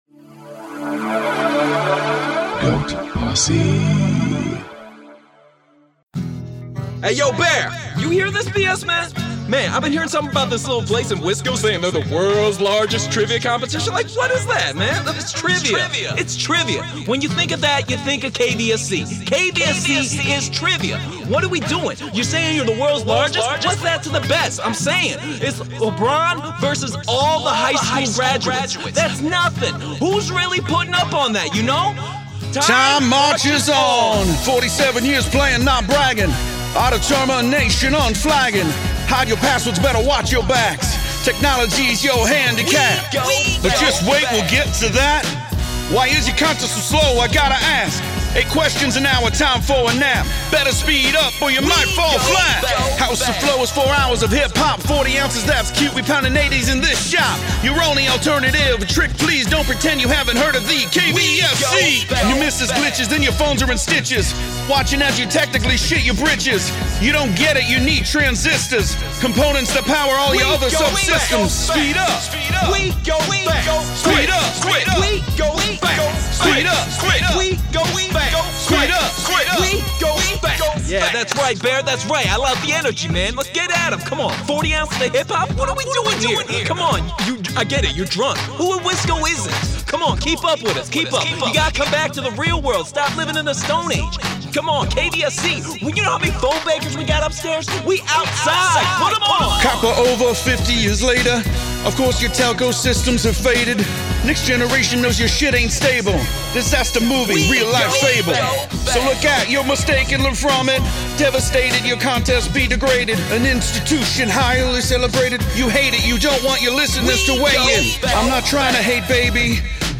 Explicit Version